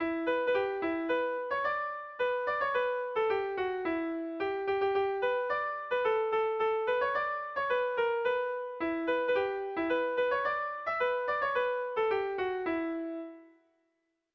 Bertso melodies - View details   To know more about this section
Erromantzea
ABDAB